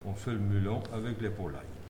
Mots Clé foin, fenaison ; Localisation Saint-Hilaire-de-Riez
Langue Maraîchin
Catégorie Locution